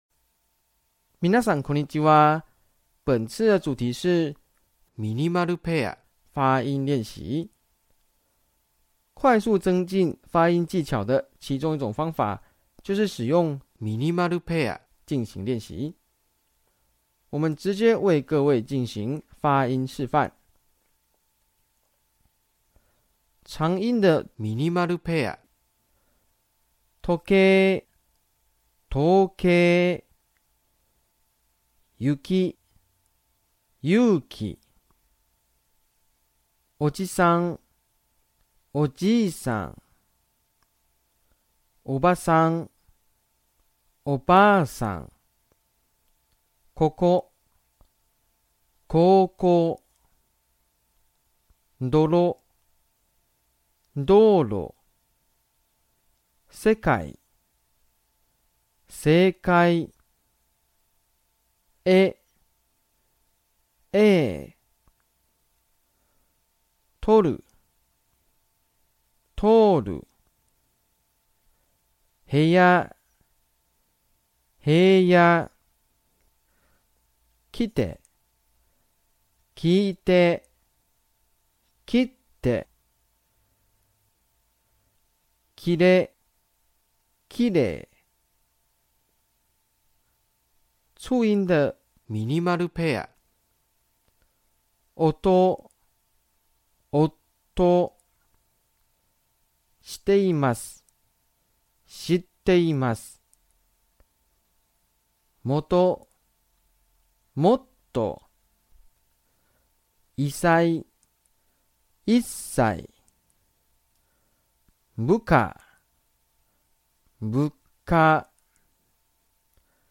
聲音解說：